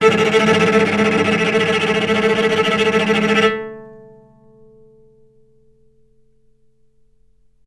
vc_trm-A3-mf.aif